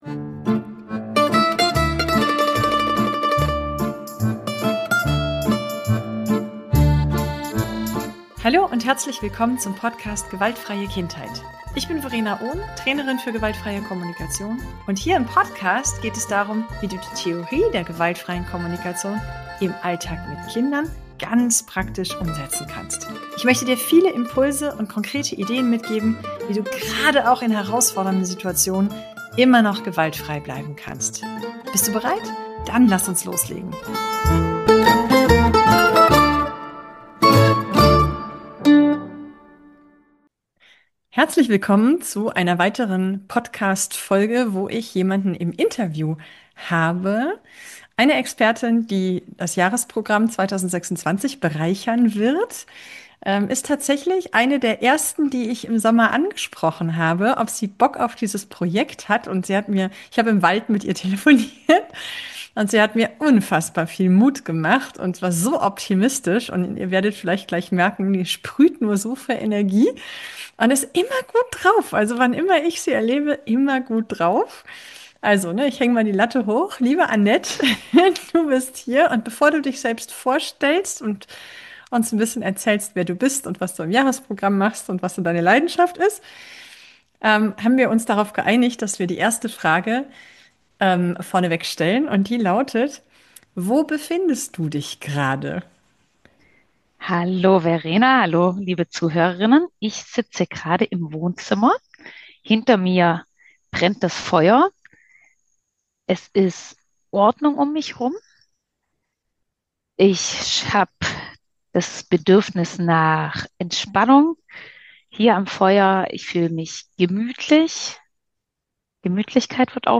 Es geht um weit mehr als aufräumen: um Energie, Bedürfnisse, Gefühle, Selbstwirksamkeit – und darum, warum Ordnung kein Selbstzweck ist, sondern eine Strategie, die uns dienen kann. Dieses Gespräch ist persönlich, tief und praktisch zugleich.